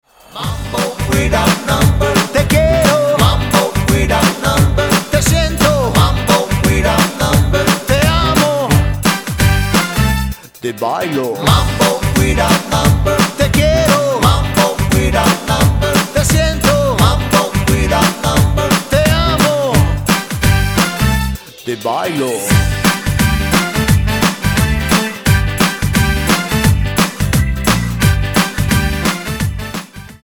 MAMBO  (3.08)